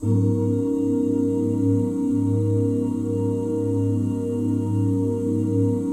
OOHG#SUS13.wav